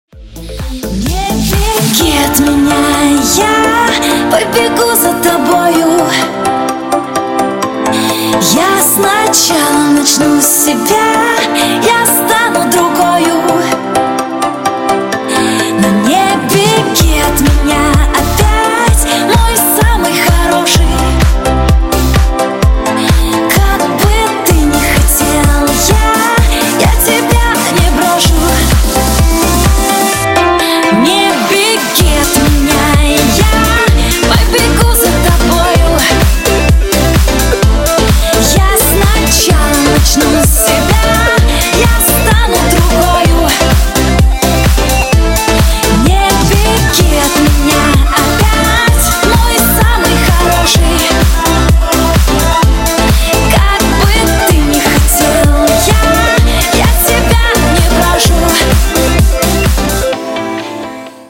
• Качество: 128, Stereo
громкие
женский вокал
мелодичные
попса
красивый женский голос